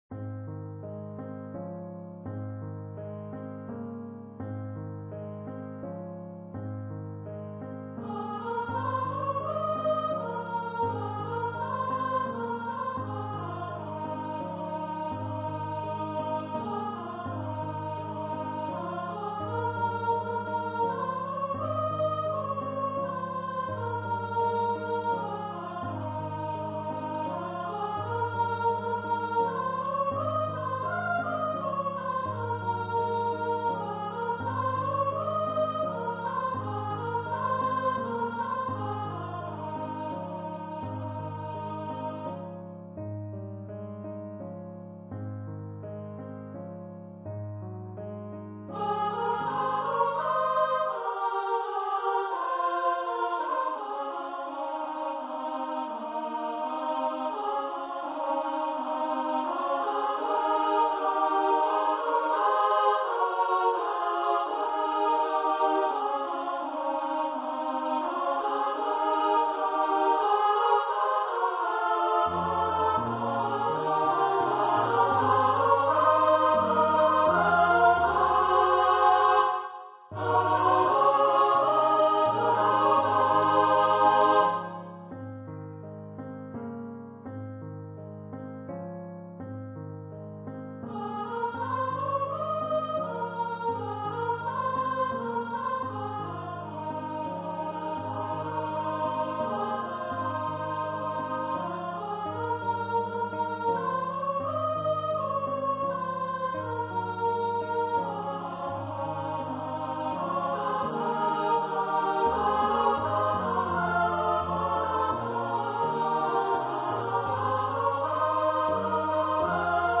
for female voice choir
Choir - 3 part upper voices